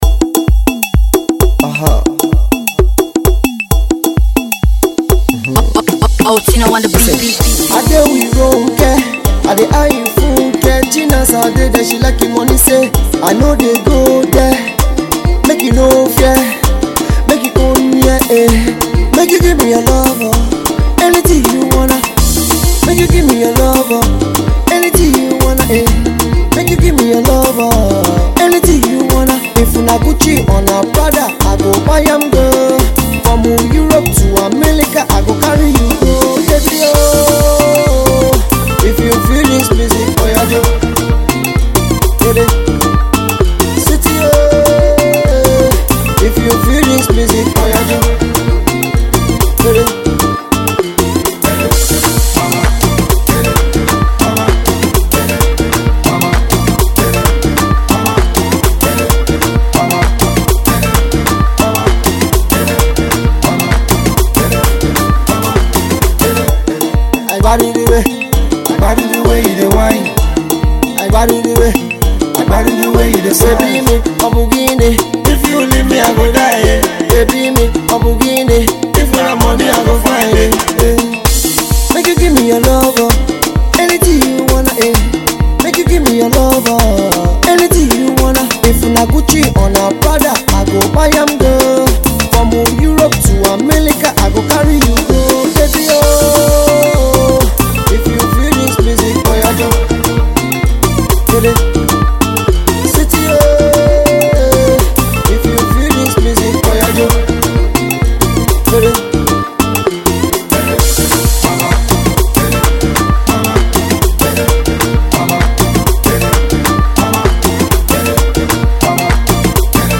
Its a Good Pop single